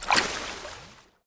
Add footstep sounds for water and lava
default_water_footstep.1.ogg